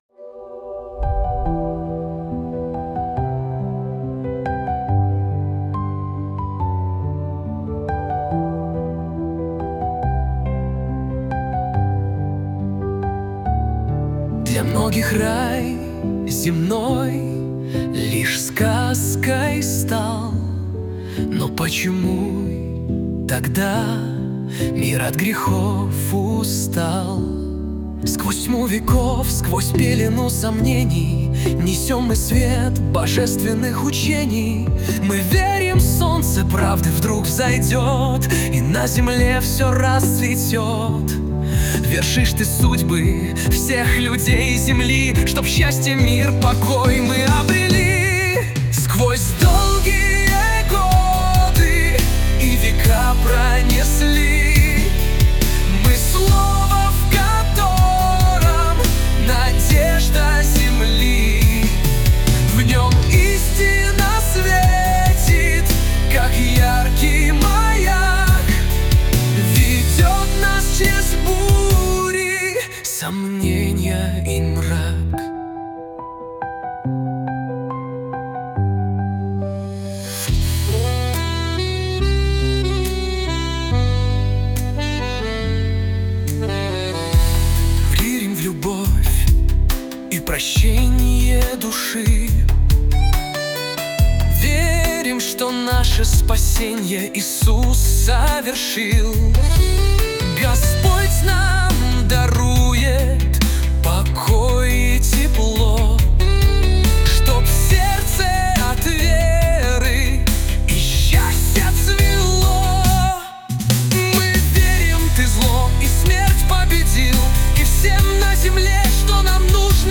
песня ai
336 просмотров 1452 прослушивания 112 скачиваний BPM: 69